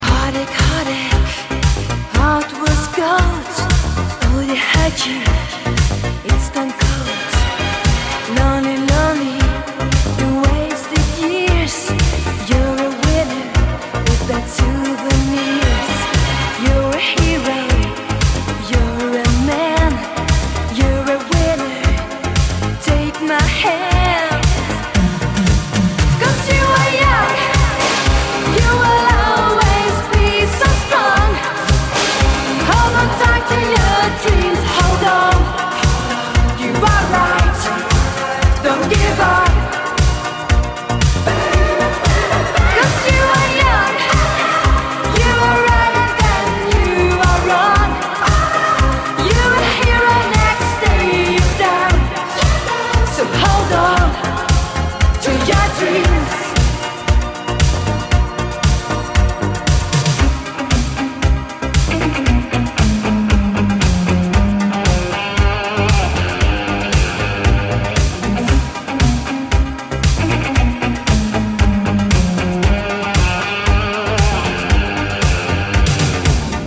• Качество: 192, Stereo
громкие
зажигательные
80-е
Зажигательное ретро